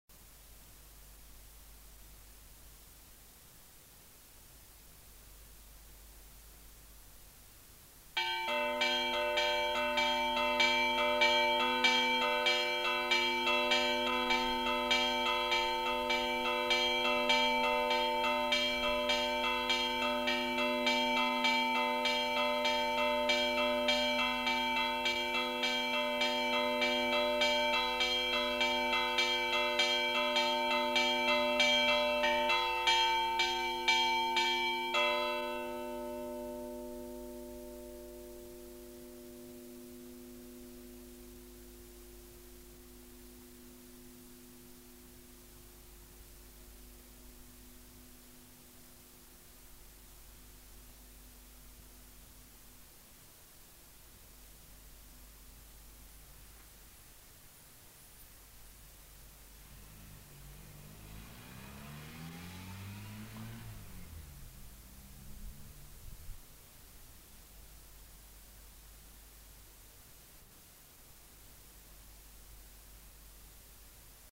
Lieu : Buzan
Genre : paysage sonore
Instrument de musique : cloche d'église